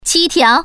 Index of /mahjong_paohuzi_Common_test/update/1658/res/sfx/putonghua/woman/